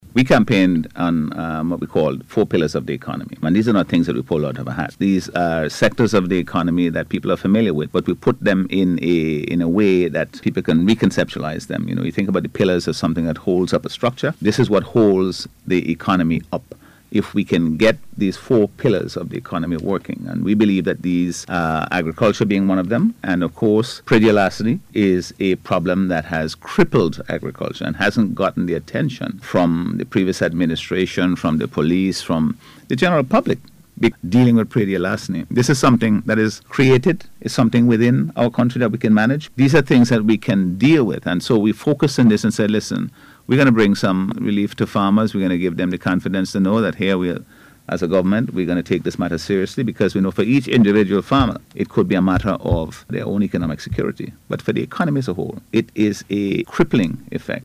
Prime Minister Dr. the Hon. Godwin Friday, speaking on NBC Radio recently, linked praedial larceny to a wider economic challenge, calling it a threat to individual farmers’ security and the nation’s food resilience.